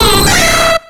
Cri de Debugant dans Pokémon X et Y.